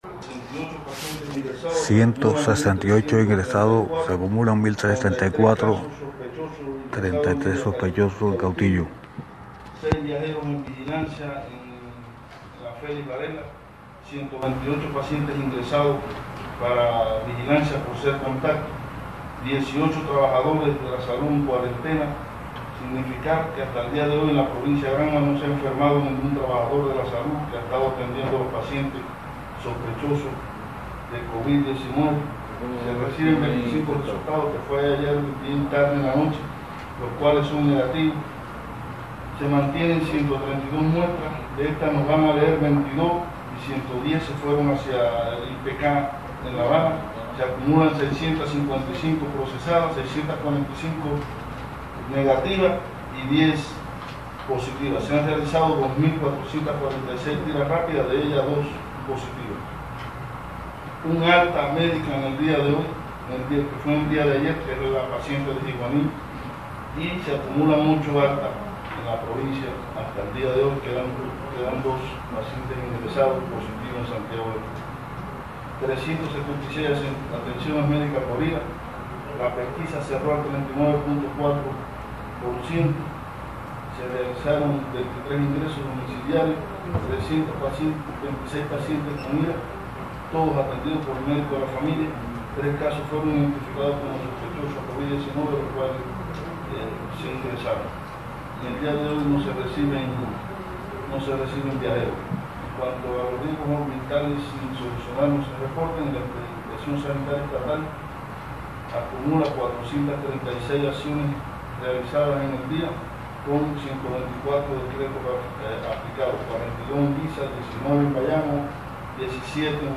El Doctor Ciro Estrada García, Director de Salud en Granma informó que se recibieron 50 resultados de PCR en tiempo real, estudiados en los laboratorios de Santiago de Cuba, todos negativos, para una tasa de incidencia de la COVID-19 de 1.2 por cada cien mil habitantes.